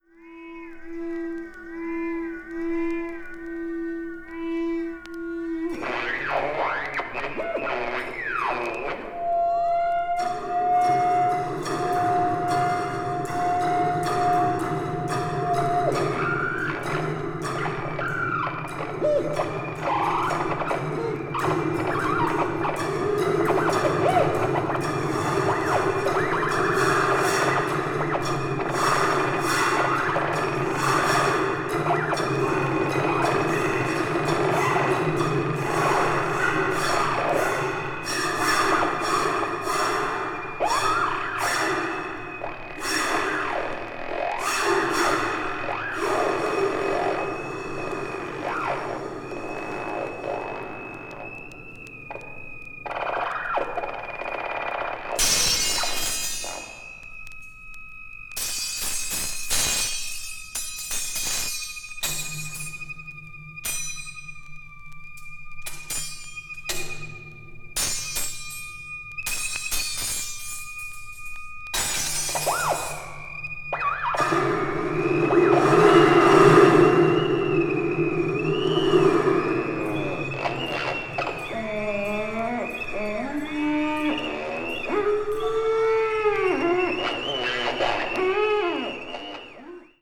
avant-garde   experimental   free improvisation   sound art